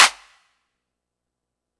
MB Clap (14).wav